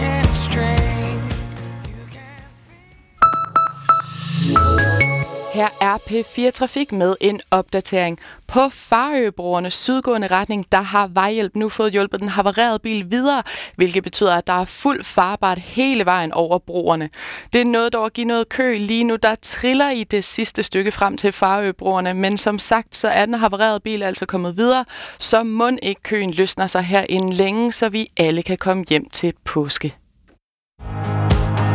Radiomeldinger, P4
Trafikmelding, P4 Sjælland, den 7. april 2023: